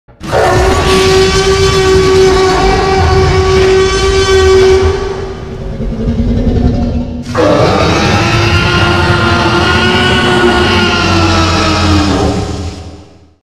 T Rex Roar HQ.mp3
t-rex-roar-hq.mp3